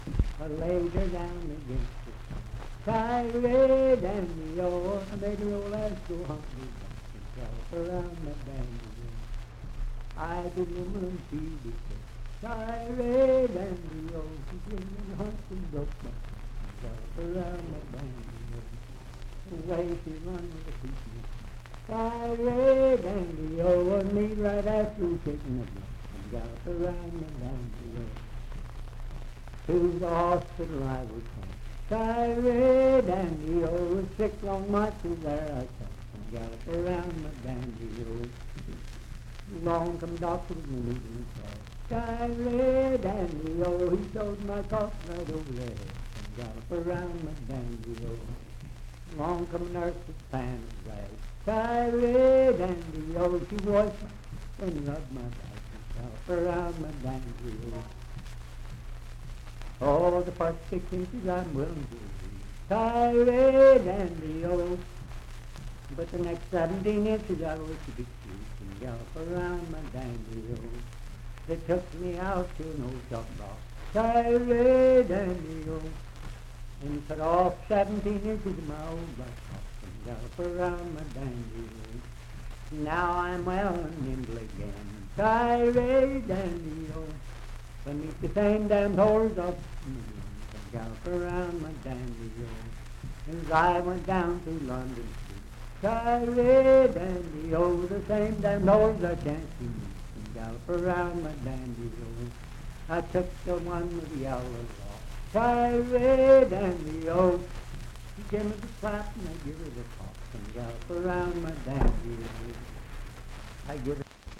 Unaccompanied vocal music and folktales
Bawdy Songs
Voice (sung)
Parkersburg (W. Va.), Wood County (W. Va.)